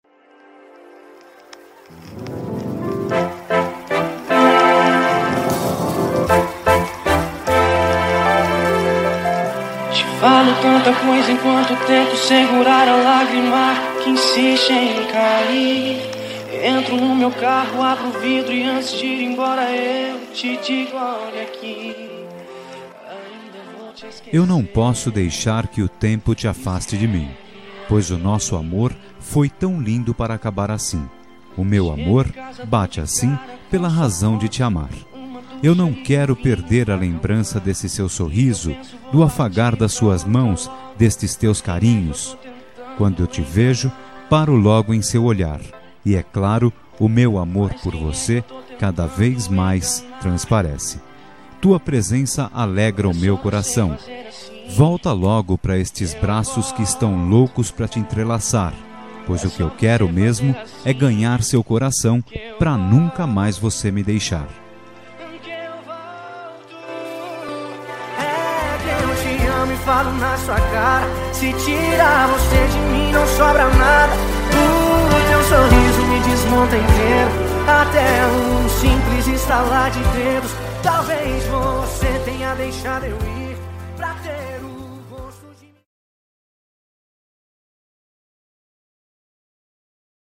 Telemensagem de Reconciliação – Voz Masculina – Cód: 035311